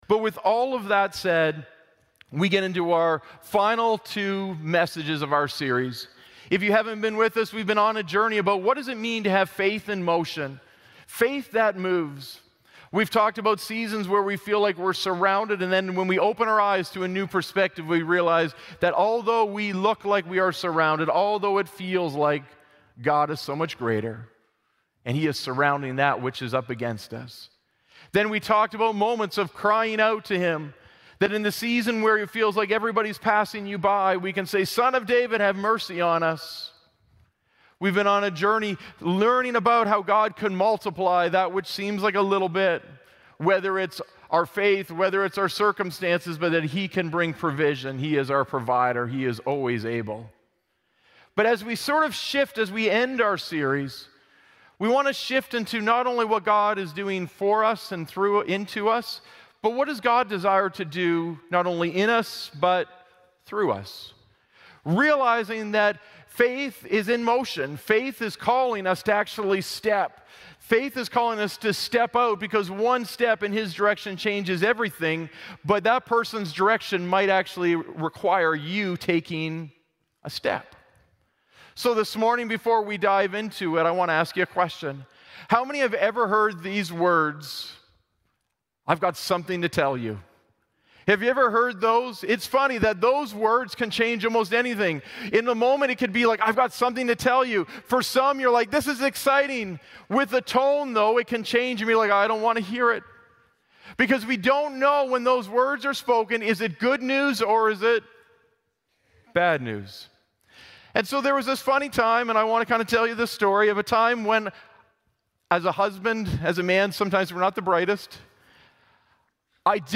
Glad Tidings Church (Sudbury) - Sermon Podcast Faith On The Go Play Episode Pause Episode Mute/Unmute Episode Rewind 10 Seconds 1x Fast Forward 30 seconds 00:00 / 10:53 Subscribe Share RSS Feed Share Link Embed